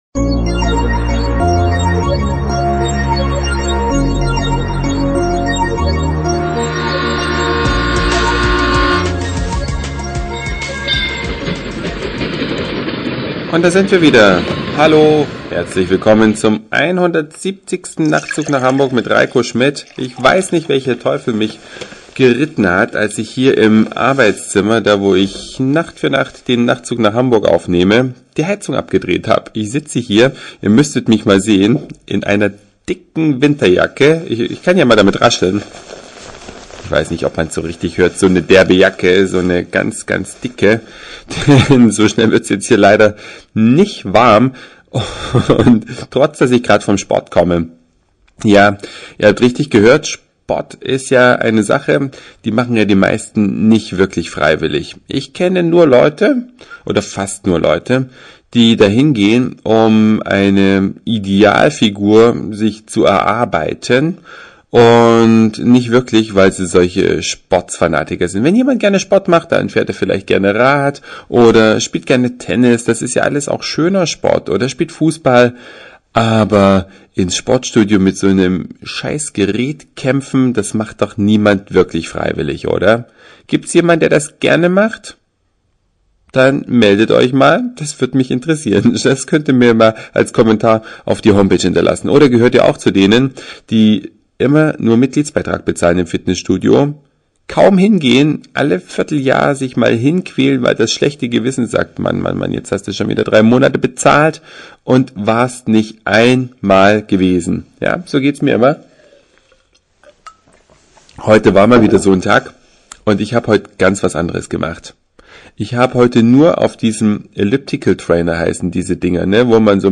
Aus dem winterkalten Studio kommt die aktuelle Folge.